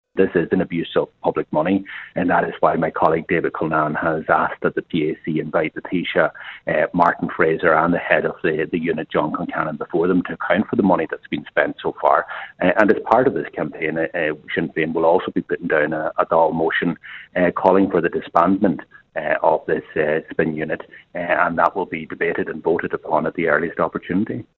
Donegal Deputy Pearse Doherty says they also want those behind the unit to appear before the Public Accounts Committee: